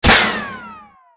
auto_hit_altmetal2.wav